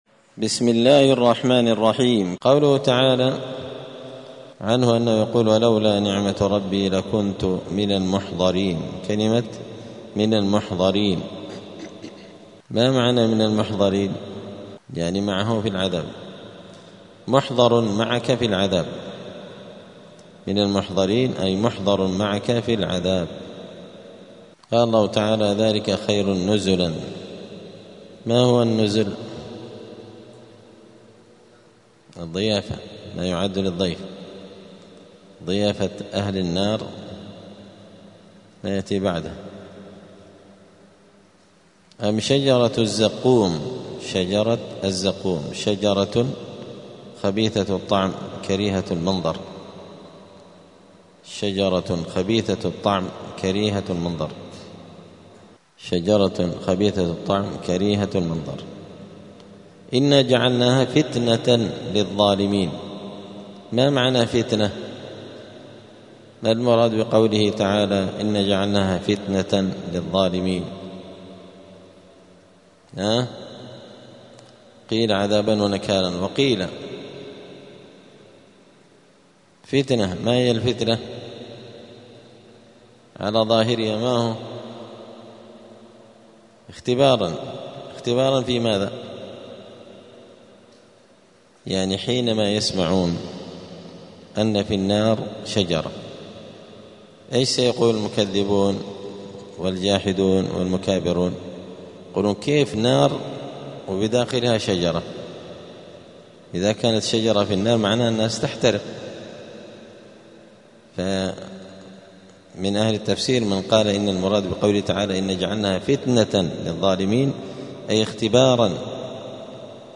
زبدة الأقوال في غريب كلام المتعال الدرس الرابع والثمانون بعد المائتين (284)
دار الحديث السلفية بمسجد الفرقان قشن المهرة اليمن